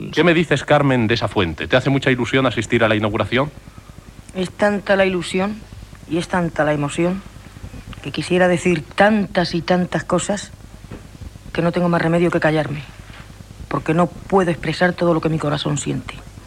Paraules de Carmen Amaya amb motiu de la inauguració d'una font dedicada a ella al barri de la Barceloneta de Barcelona.